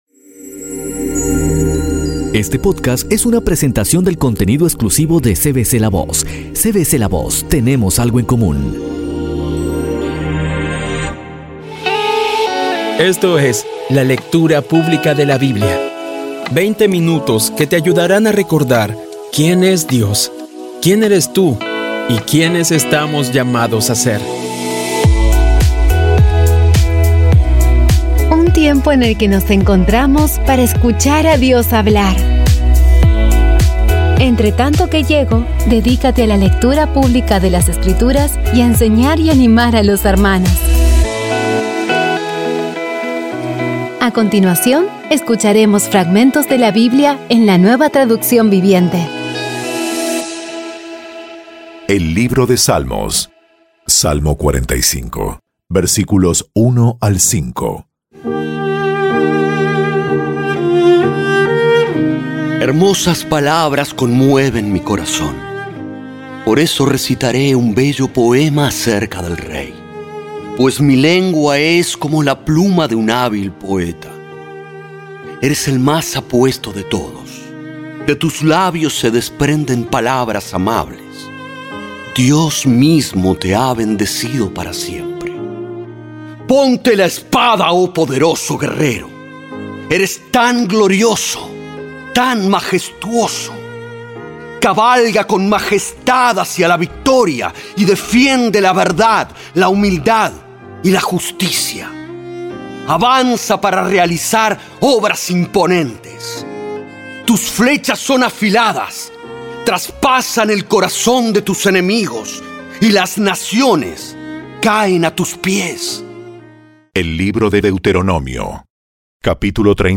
Audio Biblia Dramatizada Episodio 100
Poco a poco y con las maravillosas voces actuadas de los protagonistas vas degustando las palabras de esa guía que Dios nos dio.